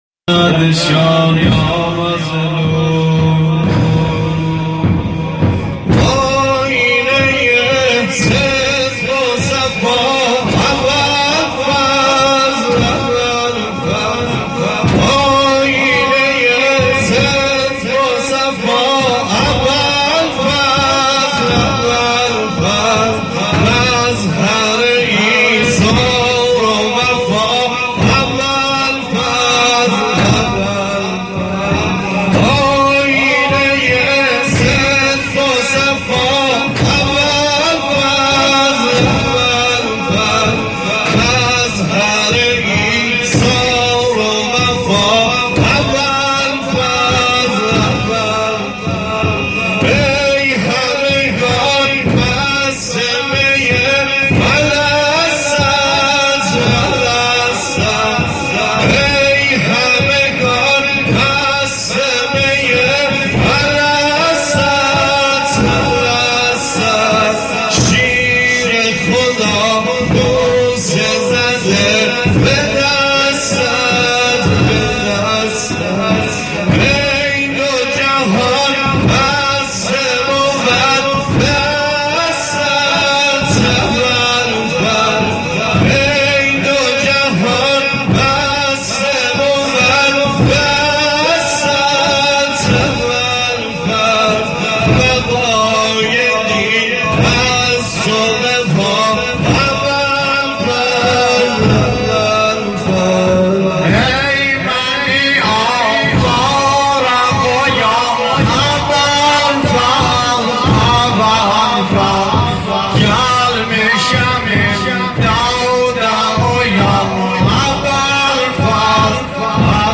محرم ۹۶(زنجیر زنی)